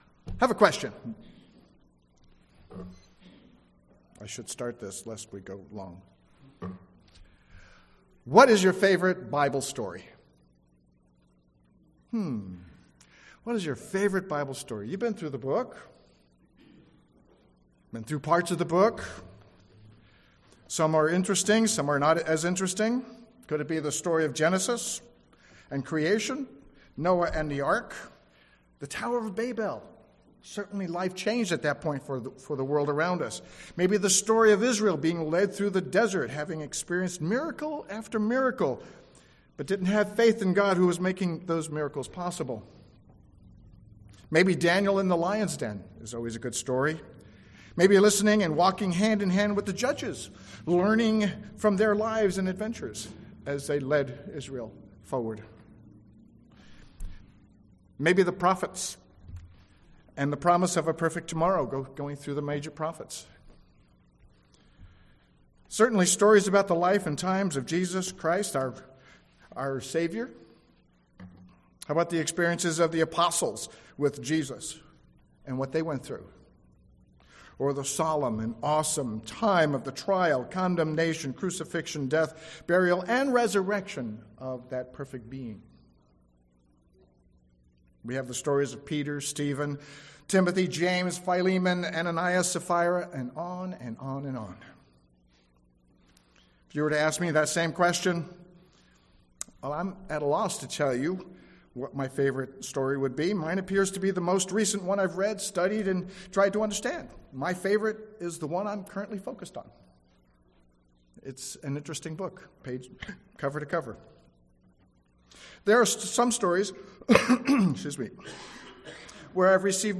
Given in San Jose, CA